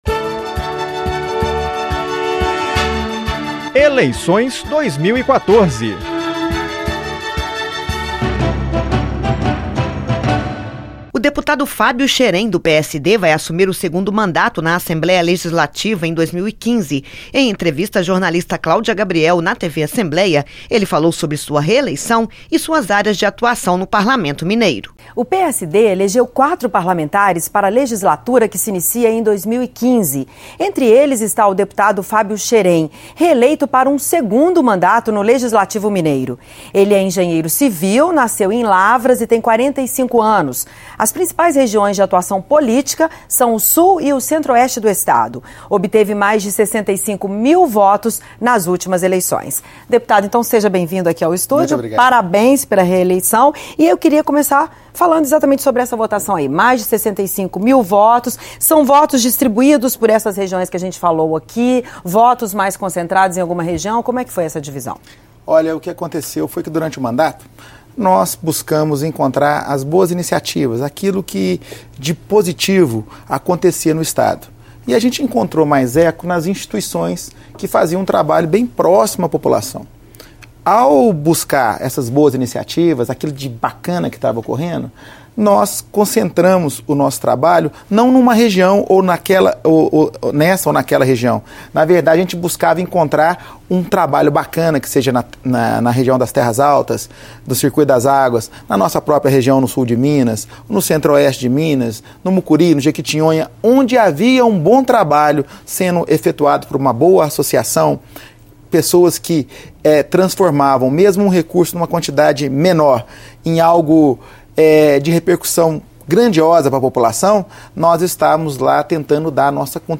Em entrevista, o parlamentar defendeu a reforma política, com destaque para o voto distrital.